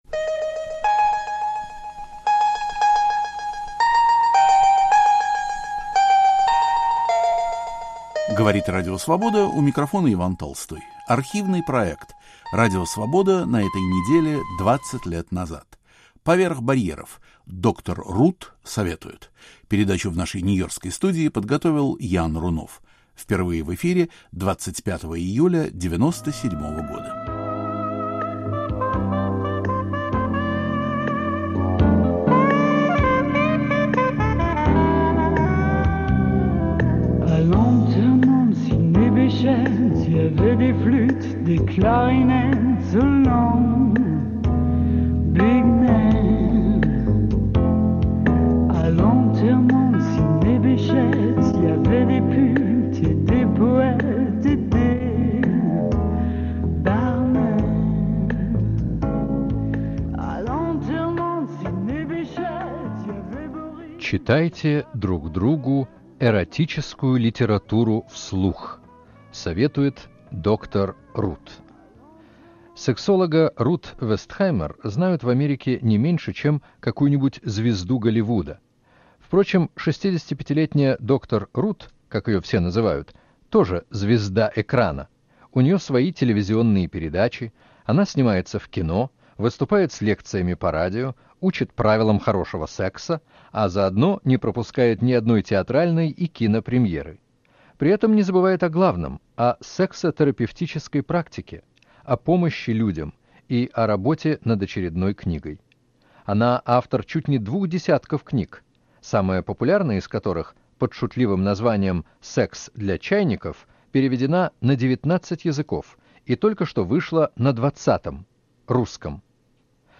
Беседа с сексологом Рут Вестхаймер (1997). Популярная в Америке врач-сексопатолог, радиоведущая и автор нескольких бестселлеров дала интервью корреспонденту Радио Свобода.